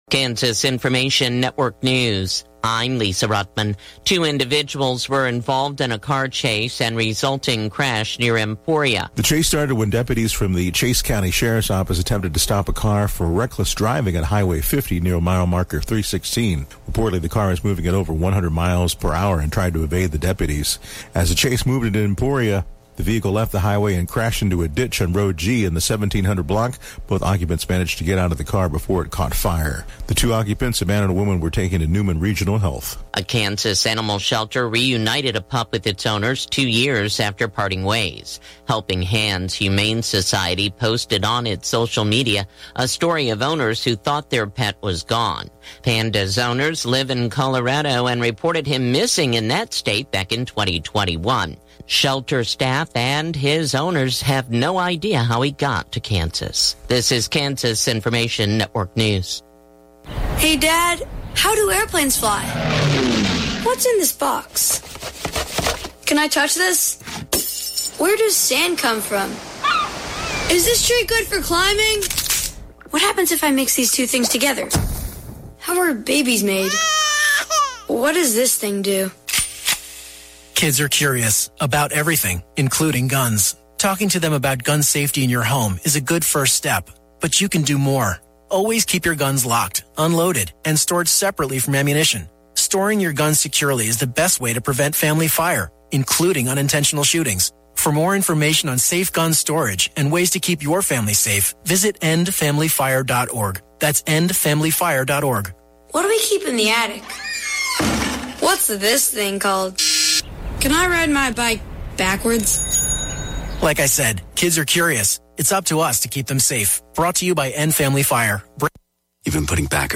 Classic Hits KQNK News, Weather & Sports Update – 8/29/2023